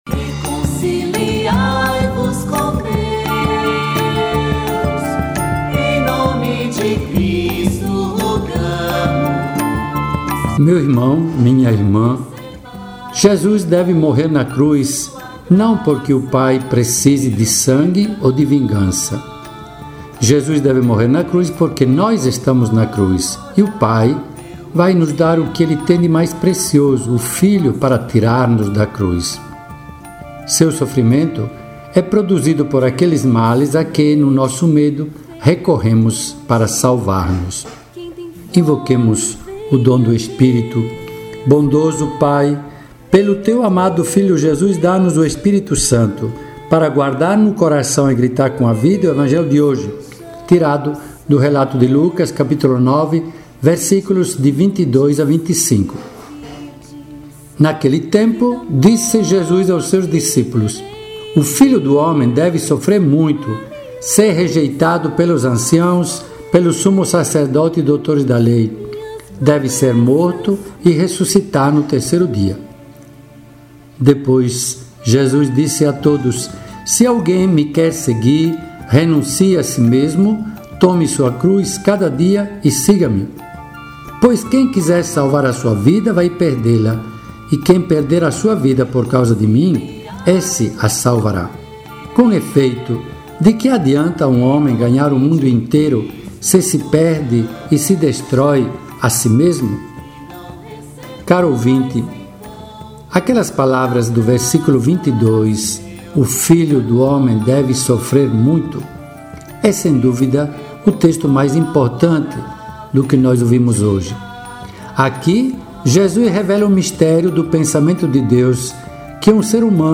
Diariamente neste período da Quaresma, o Bispo Diocesano de Afogados da Ingazeira Dom Egídio Bisol traz sua reflexão diária sobre os textos bíblicos e este período especial para os cristãos católicos.